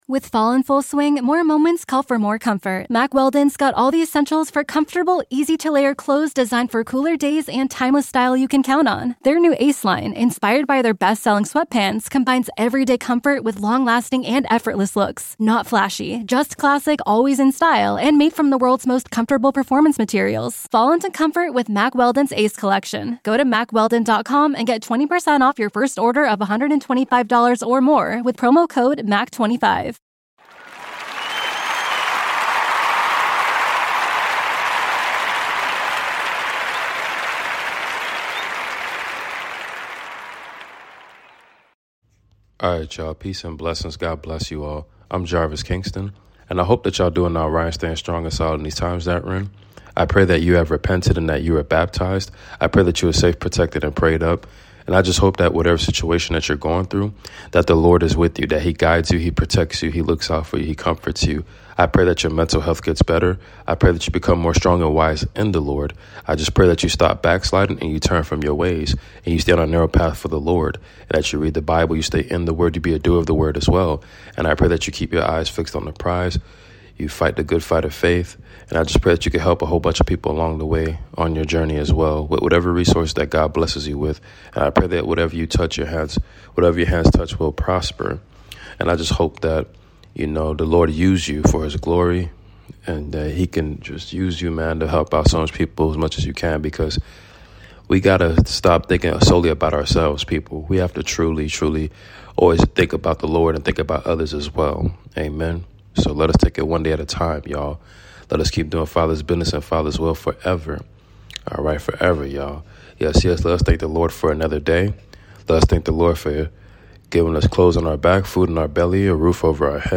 Book of Luke Chapters 2-7 reading !